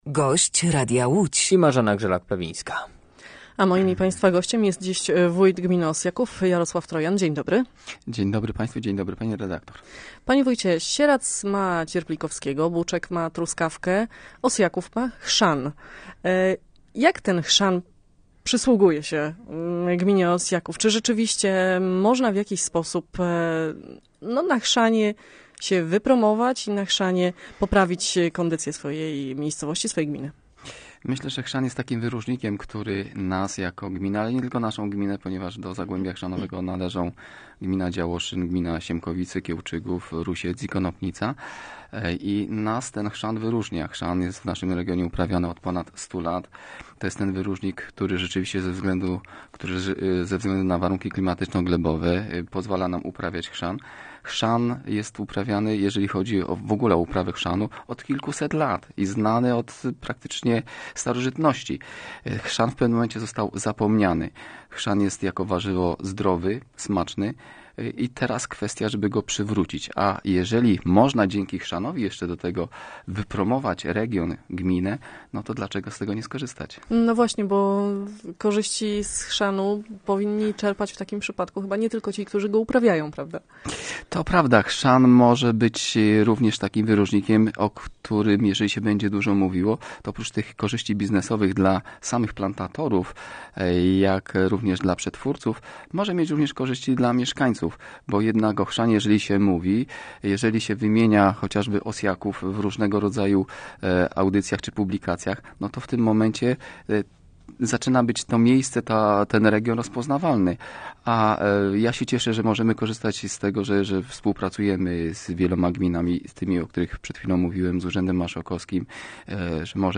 Gościem Radia Łódź Nad Wartą był wójt gminy Osjaków w powiecie wieluńskim, Jarosław Trojan.